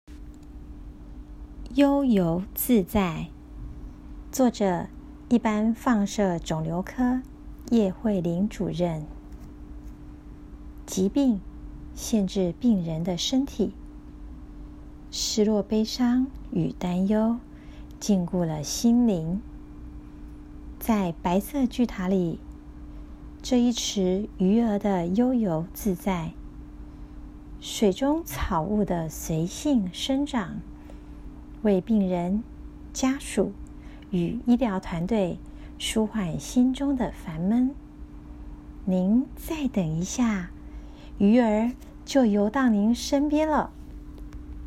語音導覽-20.悠遊自在.m4a